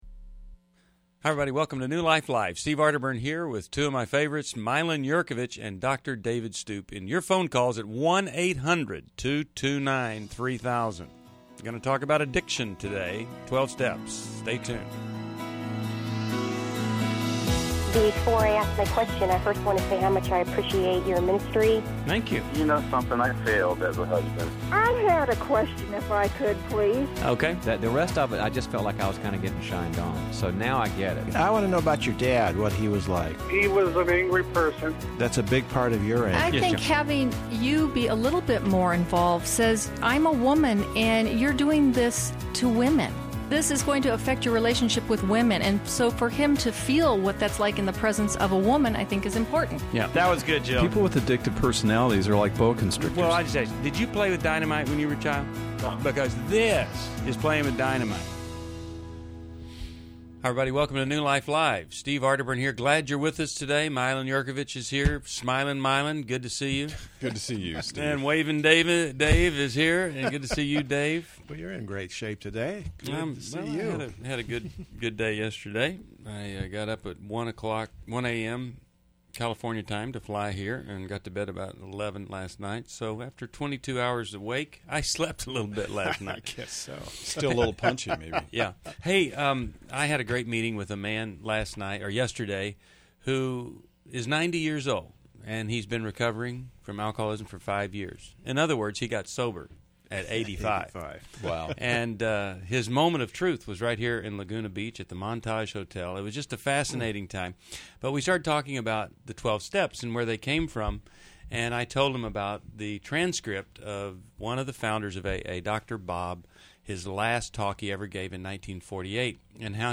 Caller Questions: 1. Should I go back to my sex addict husband? 2.